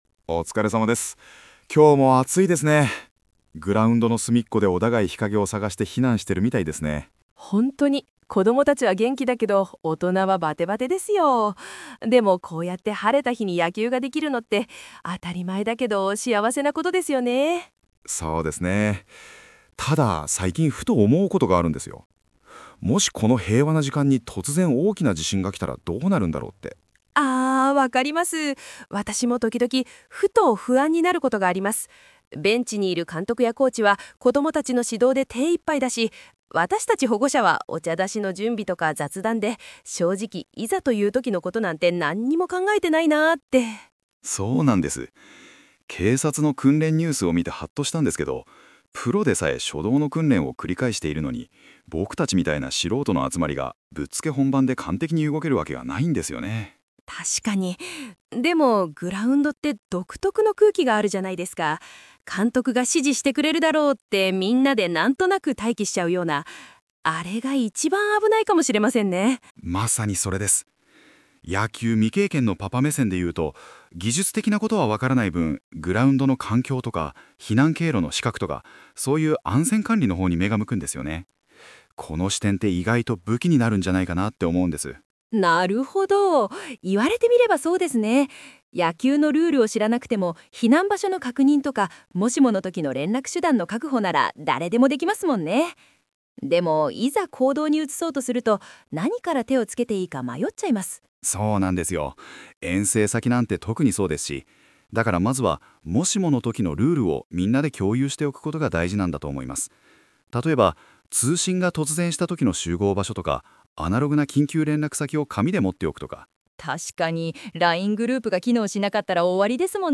※AI生成による音声コンテンツにて、発音や読み方に違和感ございますが、ご了承ねがいます。